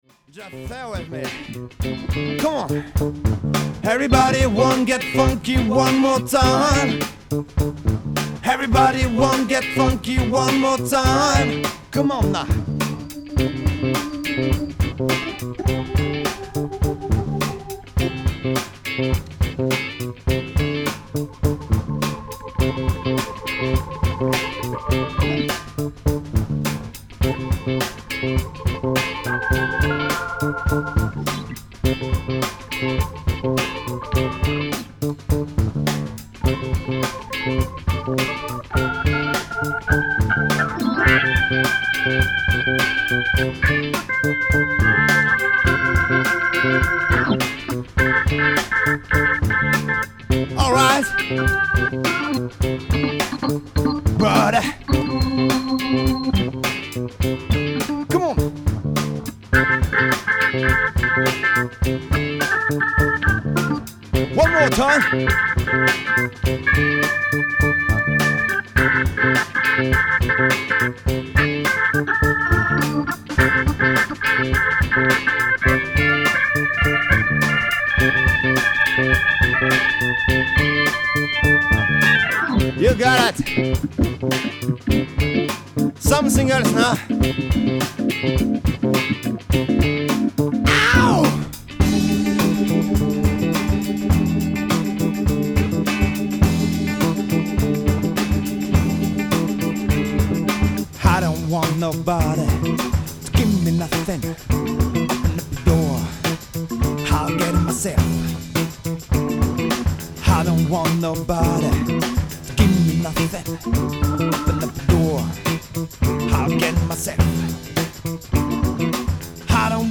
De la chanson et du funk : on baigne dans les musiques populaires.
Funky !.mp3